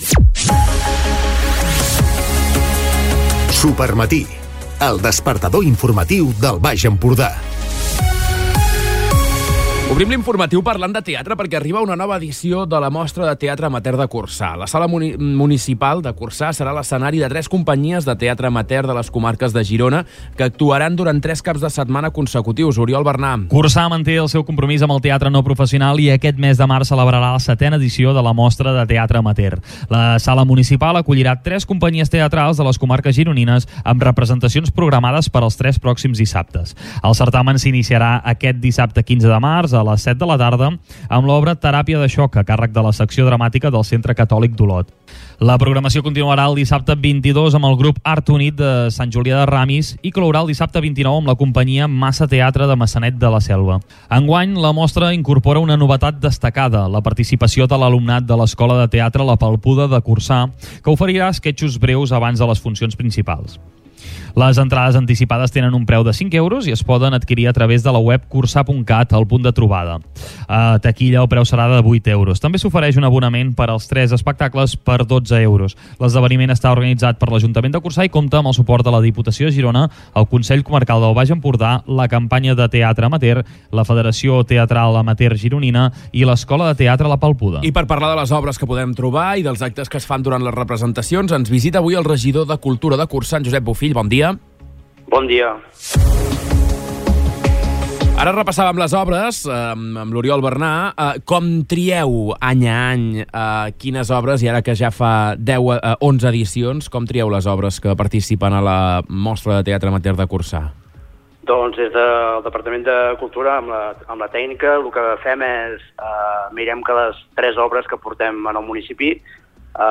Entrevistes Supermatí
La Mostra de Teatre Amateur de Corçà arriba enguany a la seva onzena edició amb una proposta clara: fer riure el públic. Segons el regidor de Cultura, Josep Bofill, la tria de les obres es fa amb la intenció de proporcionar un moment de desconnexió i entreteniment.